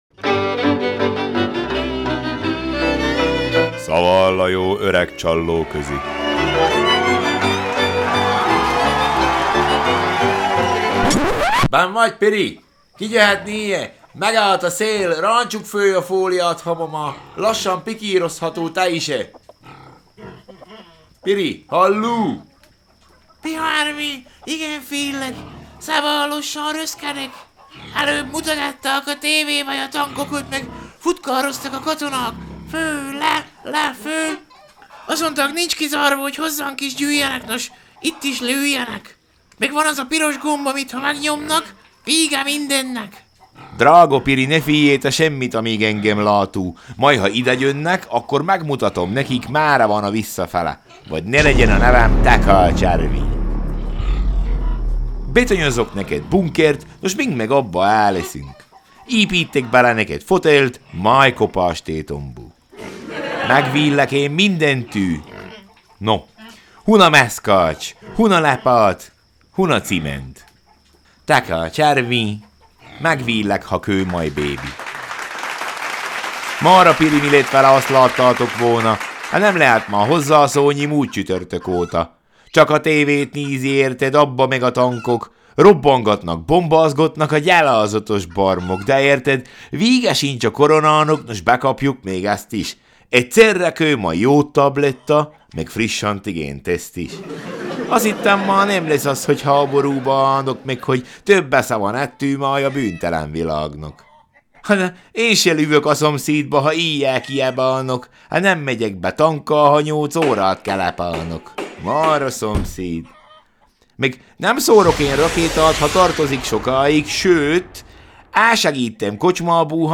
Figur Banda – Madarász Ignác verbunkja